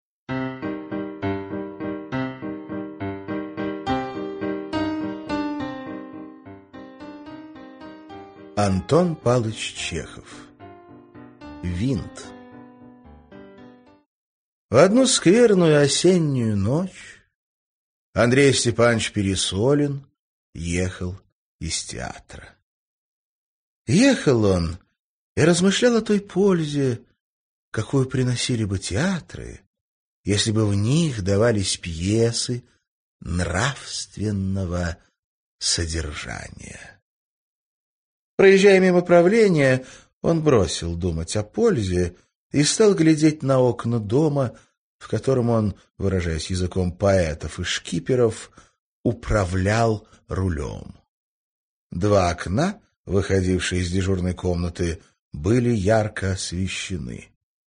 Аудиокнига Винт